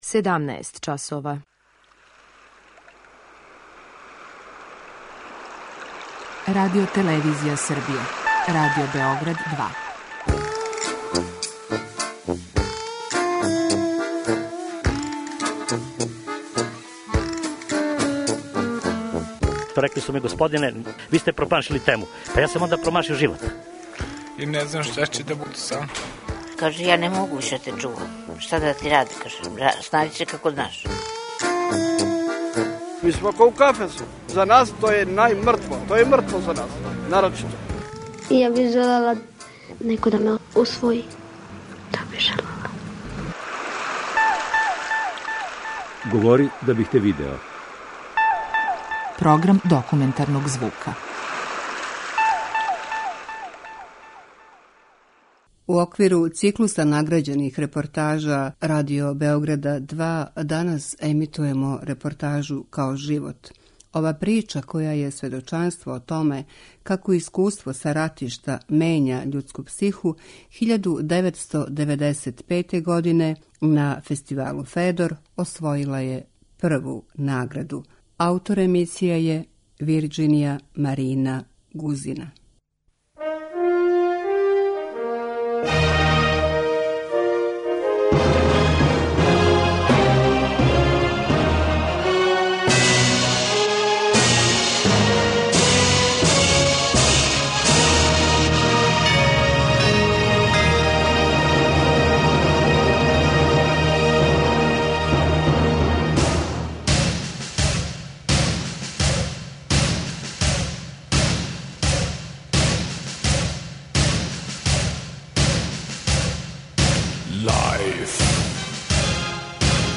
Документарни програм: Серијал награђених репортажа